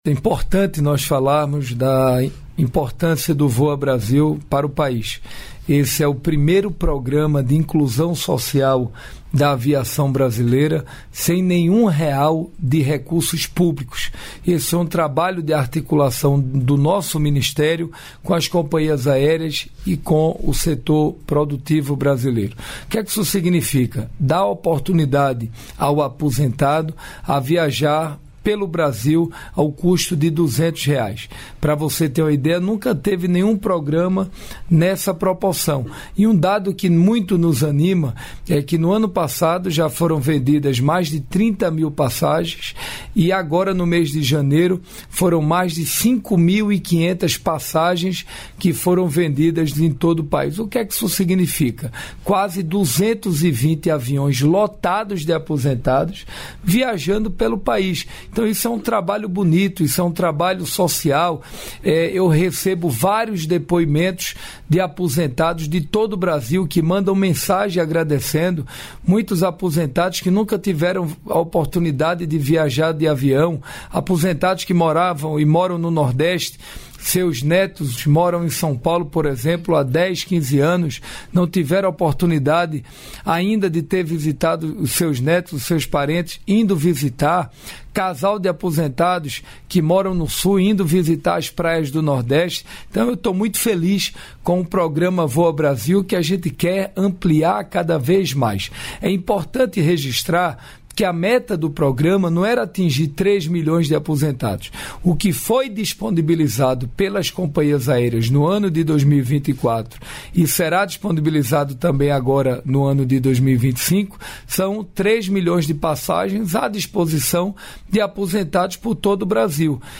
Trecho da participação do ministro de Portos e Aeroportos, Silvio Costa Filho, no programa "Bom Dia, Ministro" desta quinta-feira (06), nos estúdios da EBC, em Brasília.